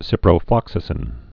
(sĭprō-flŏksə-sĭn)